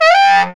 Index of /90_sSampleCDs/Roland L-CD702/VOL-2/SAX_Baritone Sax/SAX_Baritone FX